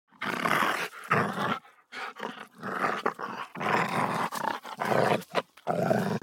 دانلود صدای سگ 7 از ساعد نیوز با لینک مستقیم و کیفیت بالا
جلوه های صوتی